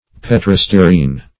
Search Result for " petrostearine" : The Collaborative International Dictionary of English v.0.48: Petrostearine \Pet`ro*ste"a*rine\, n. [Petro + stearine.] A solid unctuous material, of which candles are made.